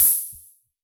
RDM_Copicat_SR88-OpHat.wav